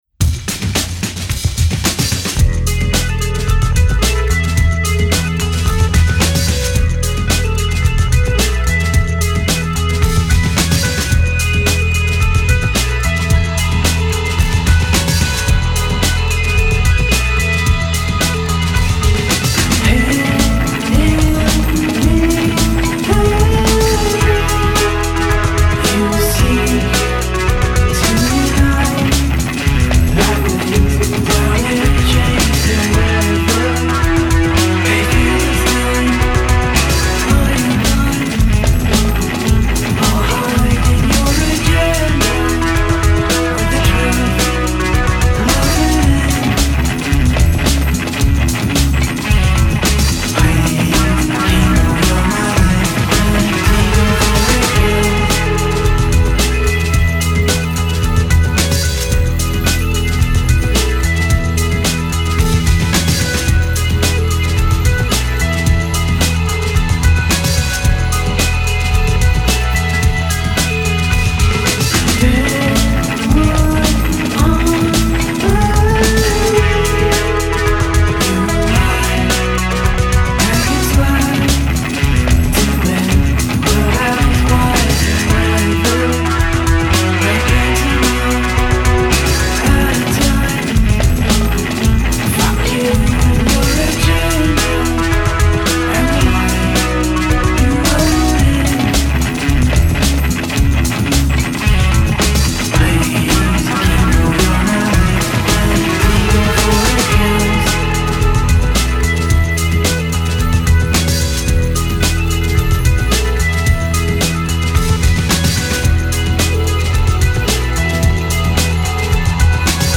And now they’ve progressed to sound like early 90s Cure.
is way more guitar based